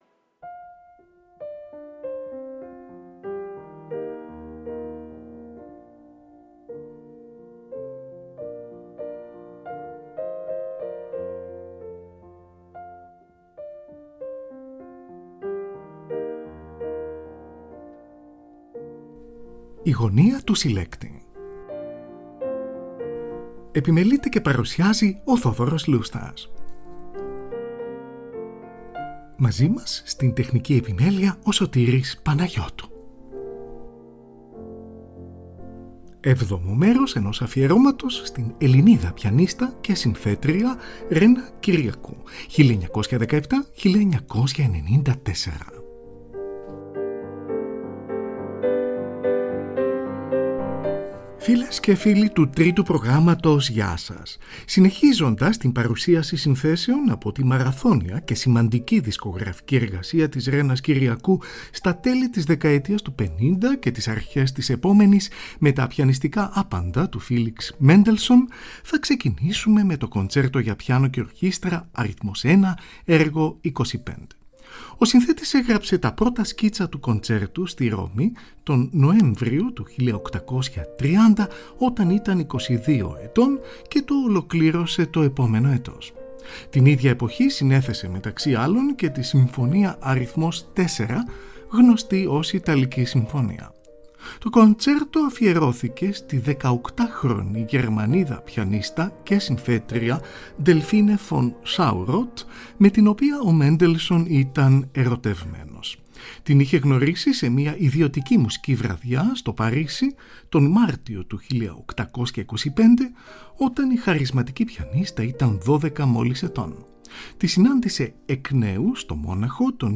Adagio cantabile e lento, από τη Σονάτα για Πιάνο αρ.2, έργο 105.